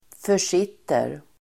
Uttal: [för_s'it:er]